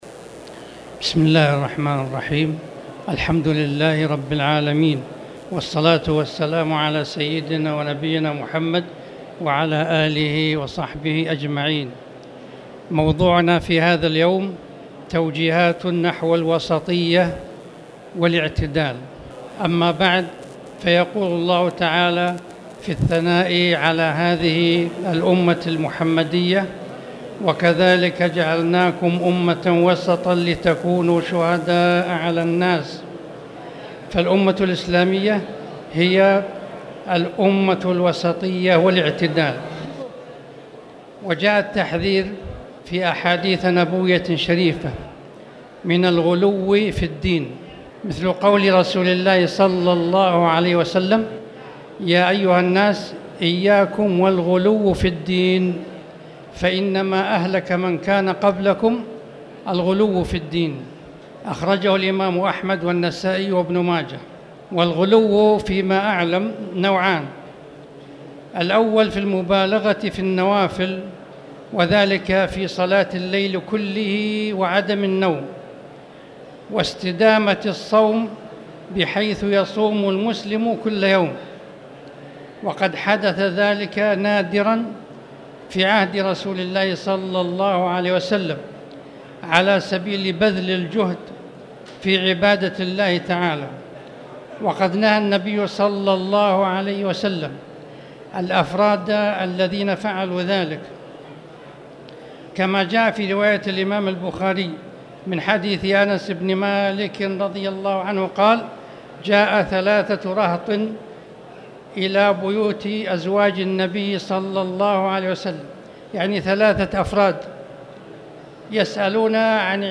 تاريخ النشر ٢١ شعبان ١٤٣٨ هـ المكان: المسجد الحرام الشيخ